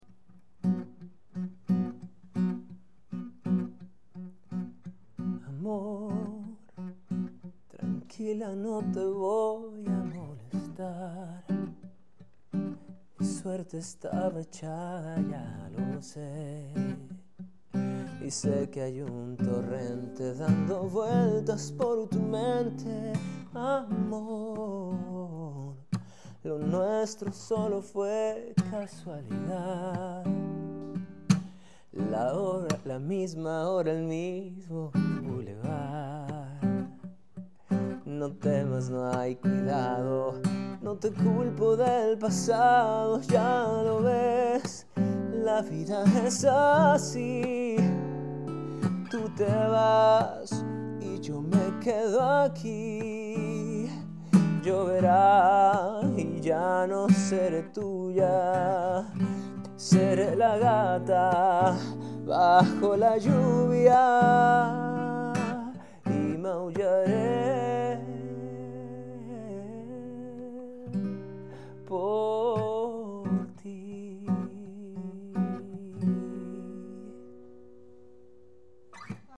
En su visita a nuestro estudio